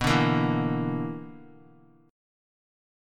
BmM7bb5 chord